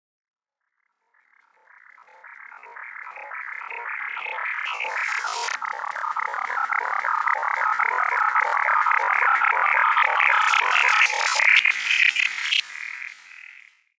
FX [ Alien ].wav